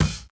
Open Hi Hat Kick MASSA.wav